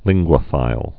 (lĭnggwə-fīl)